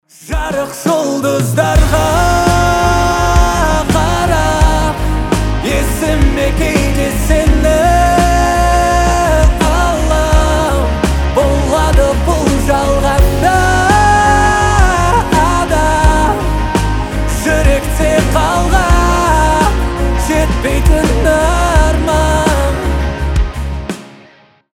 красивый мужской голос
казахские